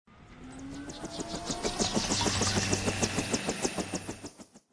ENC_propeller_out.ogg